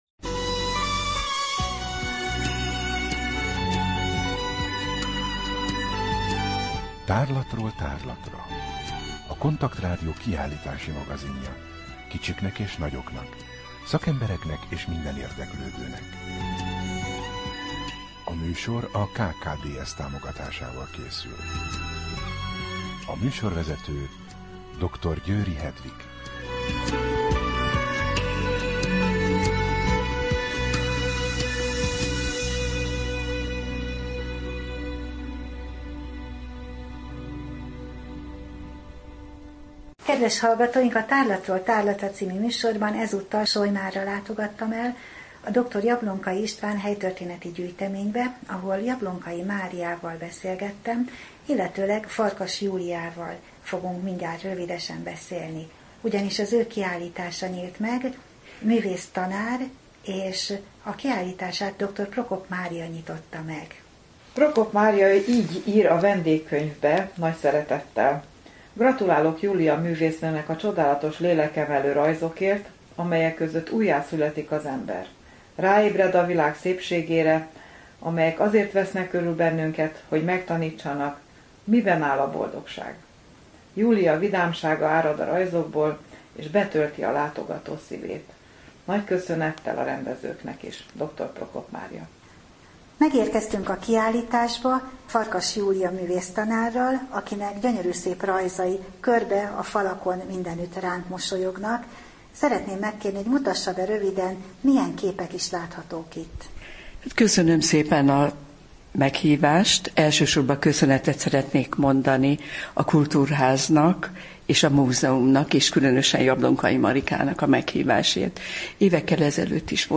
Rádió: Tárlatról tárlatra Adás dátuma: 2014, October 16 Tárlatról tárlatra / KONTAKT Rádió (87,6 MHz) 2014. október 16.
Zenei betétek: Solymári sváb énekek